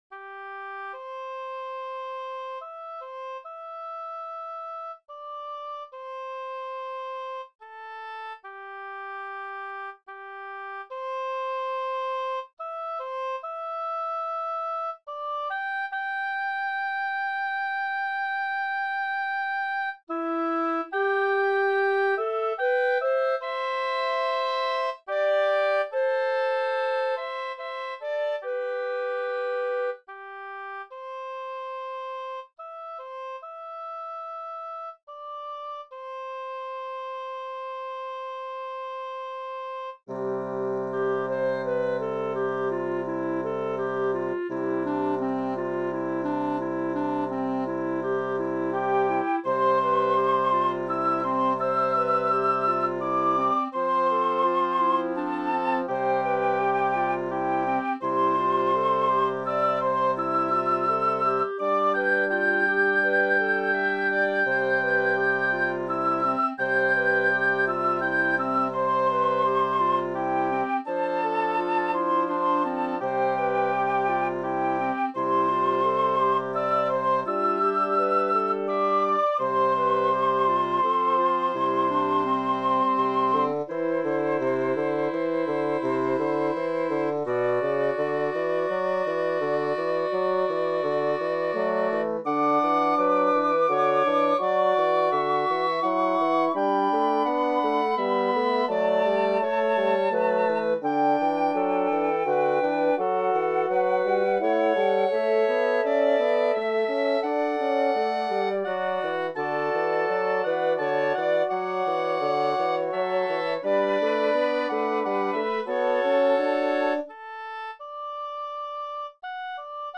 A simple yet reflective setting of this traditional hymn.